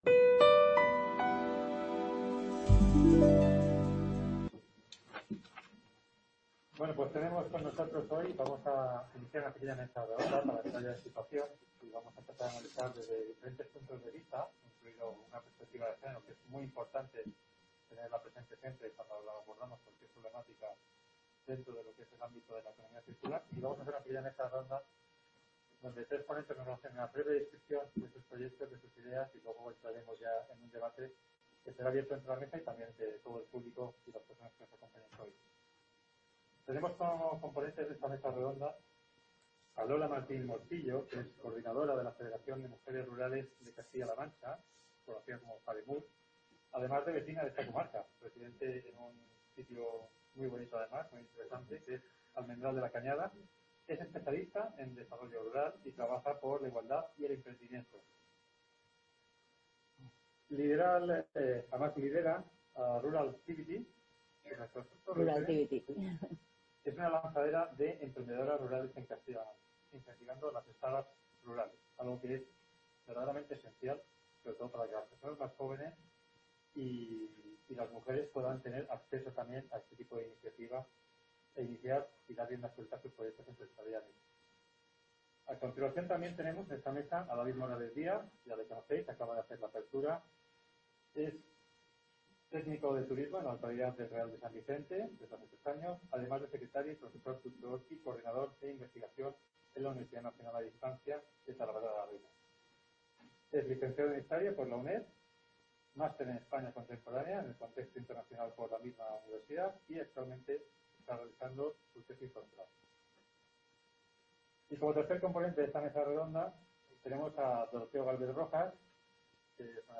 Economía circular y medio rural (Mesa Redonda) | Repositorio Digital
Jornadas dedicadas a la Economía Circular en el Mundo Rural, organizadas por la UCLM en colaboración con la UNED de Talavera de la Reina. Un espacio abierto de diálogo y propuestas para el desarrollo sostenible de las zonas rurales.